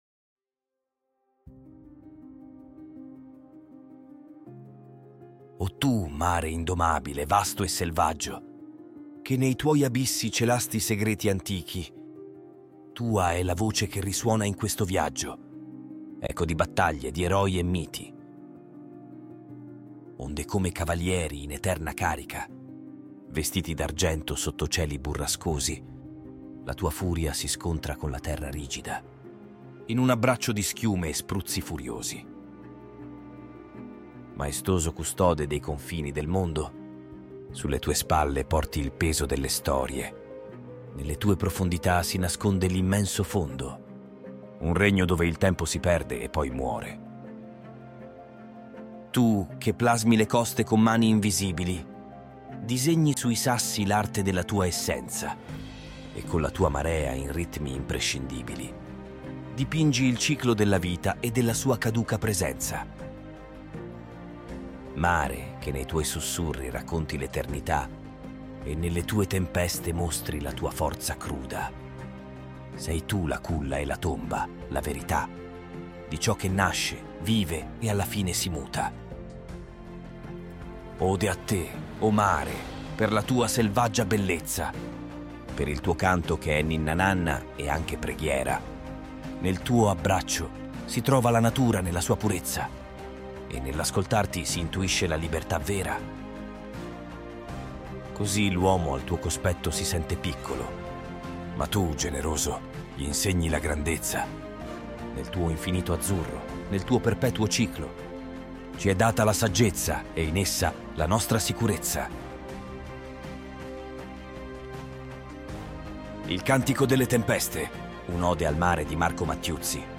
La voce sintetica utilizzata nella registrazione aggiunge un ulteriore livello di profondità e atmosfera, trasportando l’ascoltatore direttamente nelle braccia tumultuose dell’oceano.